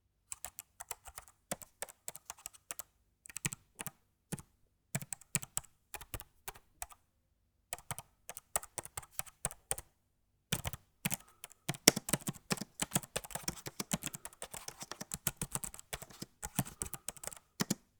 Computer keyboard typing close up
apple buttons click close computer desktop effect fast sound effect free sound royalty free Sound Effects